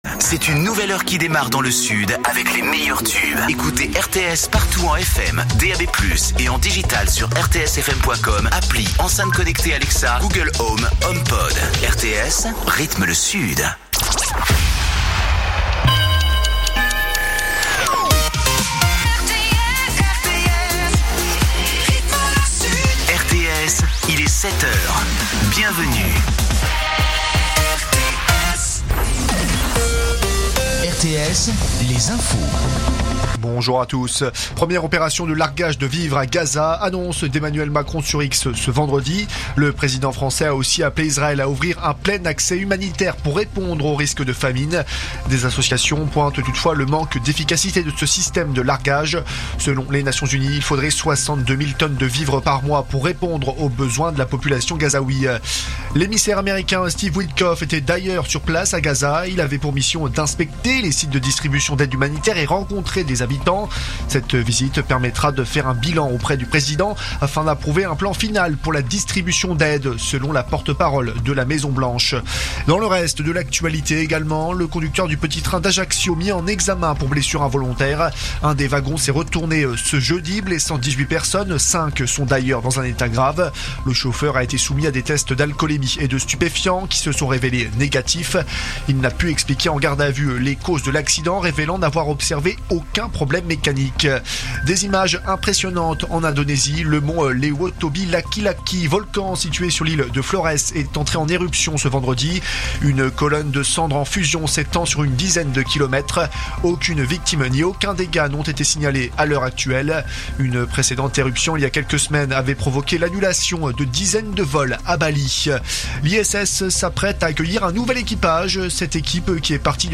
Écoutez les dernières actus d'Avignon en 3 min : faits divers, économie, politique, sport, météo. 7h,7h30,8h,8h30,9h,17h,18h,19h.